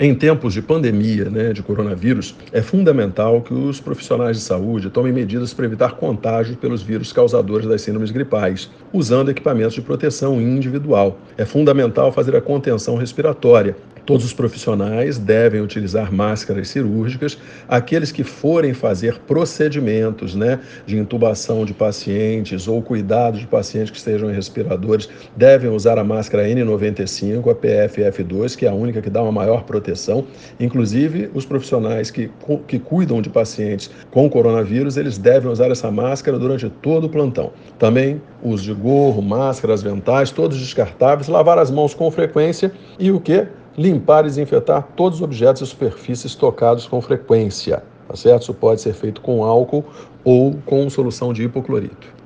Em uma pandemia os profissionais de saúde ficam ainda mais expostos. O professor de Medicina da UFF e Presidente da Comissão de Saúde da Câmara Municipal do Rio, Doutor Jorge Manaia, orienta.